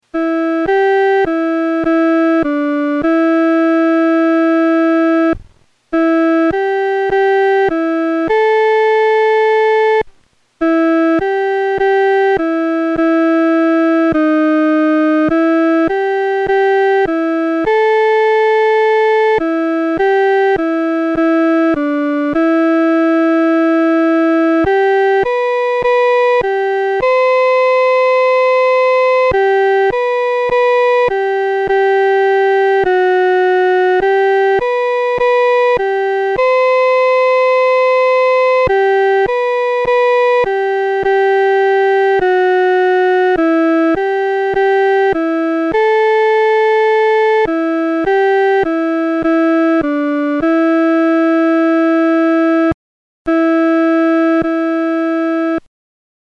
伴奏
女高